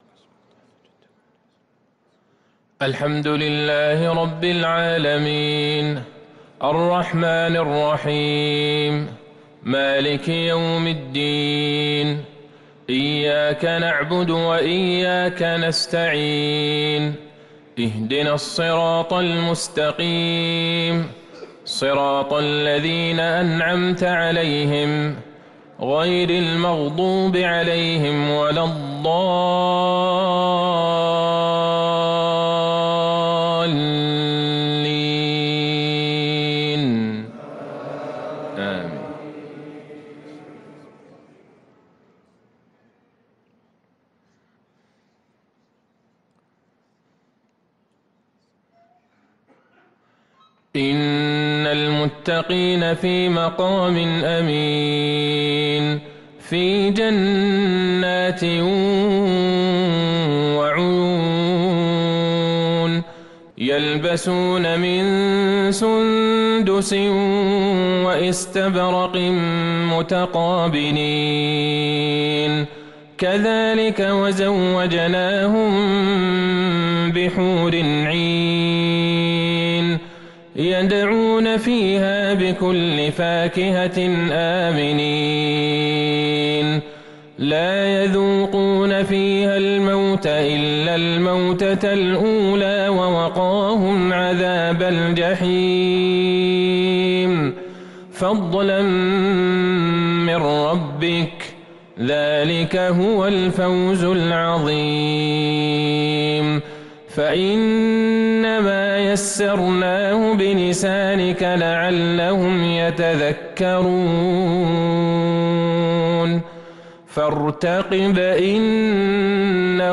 صلاة العشاء للقارئ عبدالله البعيجان 7 شوال 1443 هـ